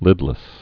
(lĭdlĭs)